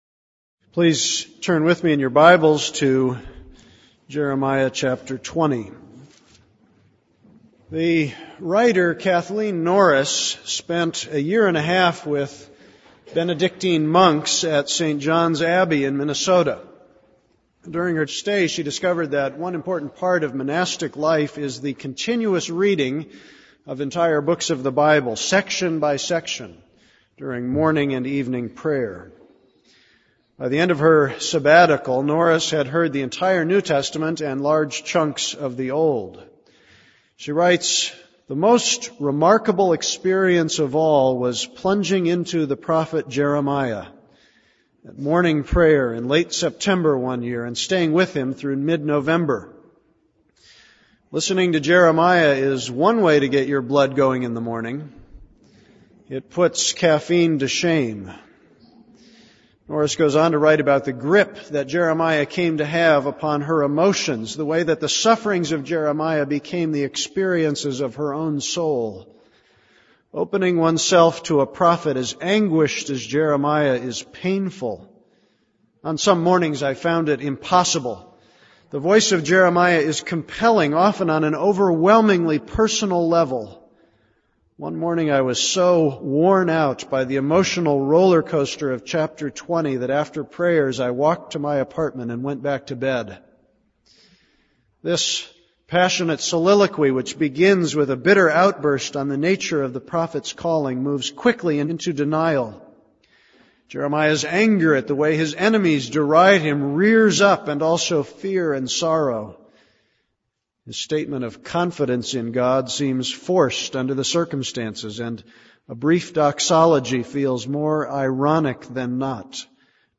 This is a sermon on Jeremiah 20:8-9.